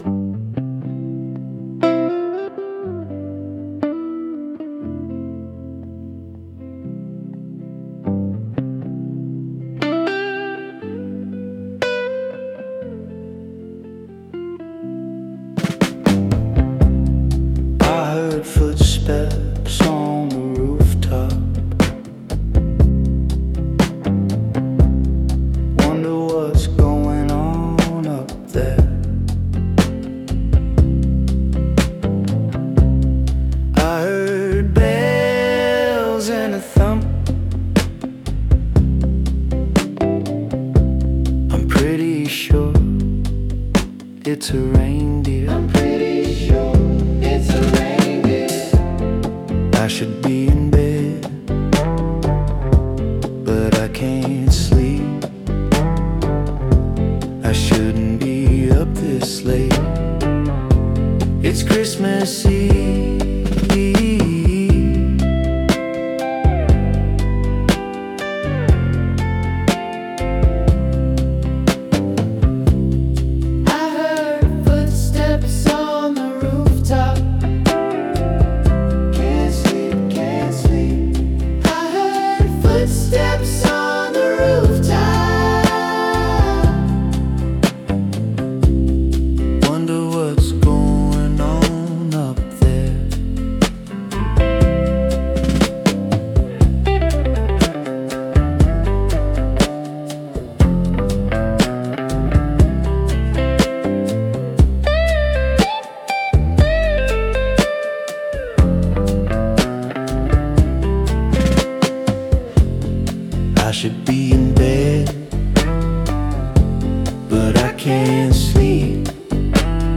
Chill Christmas songs